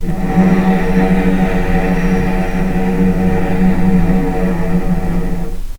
healing-soundscapes/Sound Banks/HSS_OP_Pack/Strings/cello/sul-ponticello/vc_sp-F#2-pp.AIF at 01ef1558cb71fd5ac0c09b723e26d76a8e1b755c
vc_sp-F#2-pp.AIF